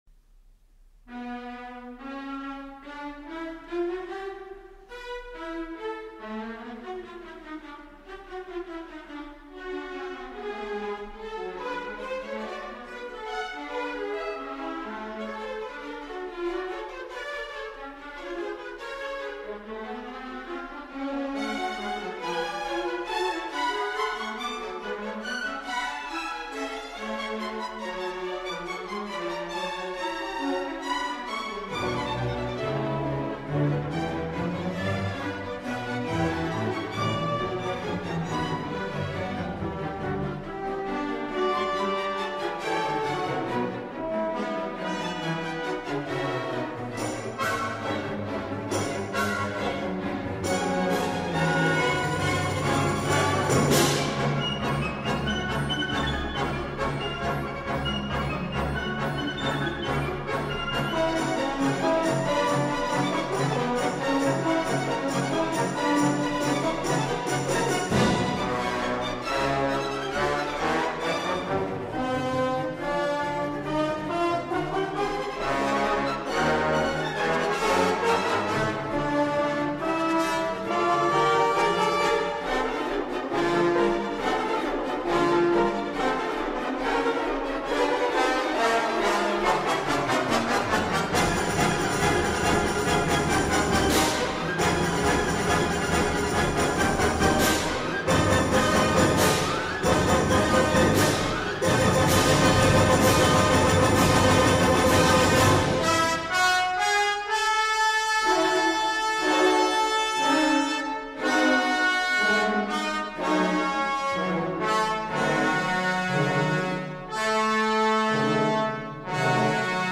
• Fragment 5: Het fugato uit het laatste deel.